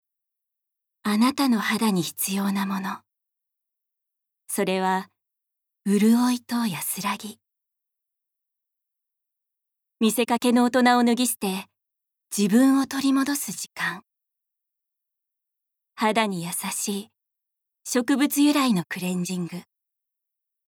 ボイスサンプル
ナレーション３